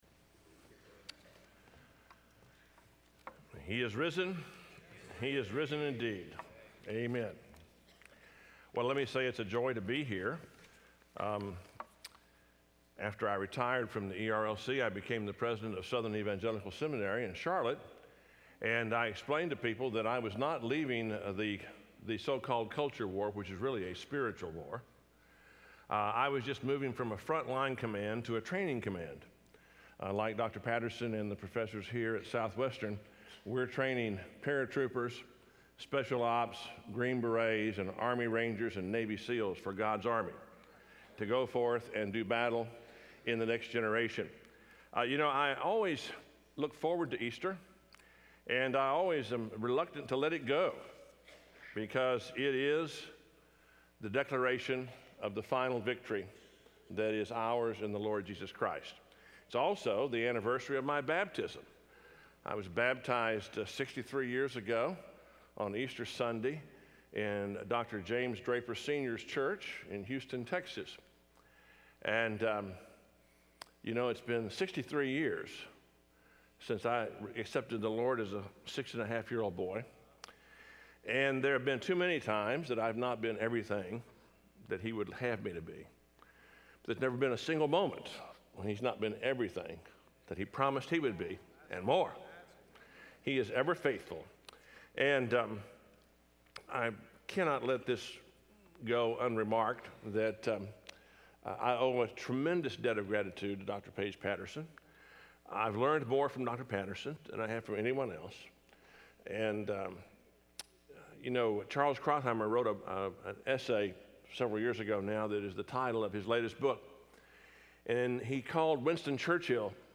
Dr. Richard Land speaking on in SWBTS Chapel on Wednesday March 30, 2016